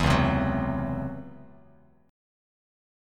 DbmM11 chord